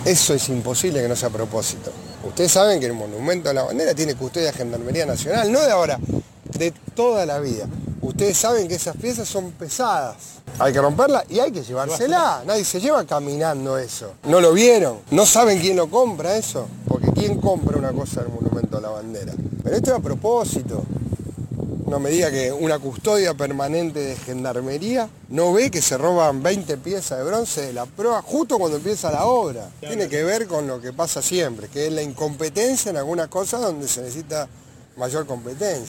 El intendente de Rosario, Pablo Javkin, habló tras el robo de 21 manijas de bronce del Monumento a la Bandera.